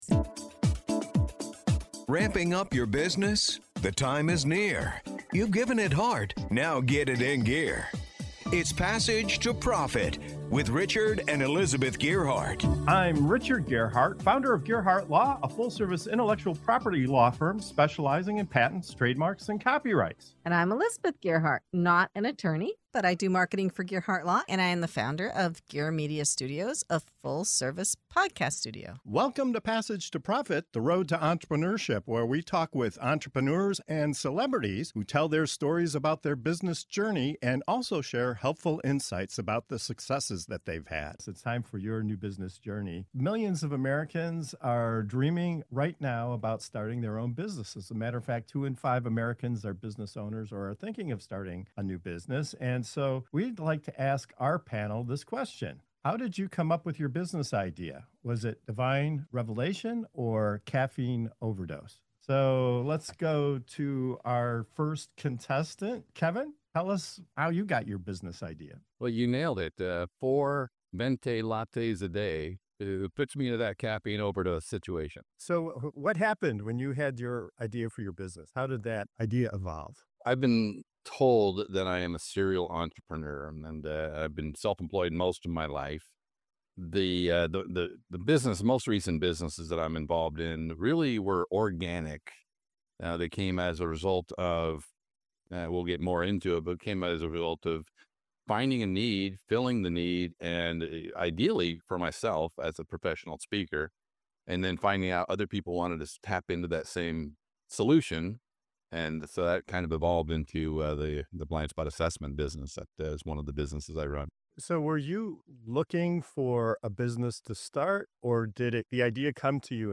What sparks a business idea—divine revelation or a caffeine overdose? In this lively and relatable segment of "Your New Business Journey" on Passage to Profit Show, our panel of entrepreneurs share the quirky and inspiring origins of their ventures—from late-night lightning bolts to spotting unmet needs in their industries.